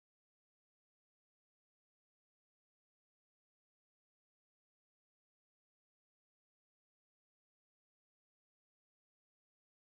خوانش تمام ابیات این برنامه - فایل صوتی
1051-Poems-Voice.mp3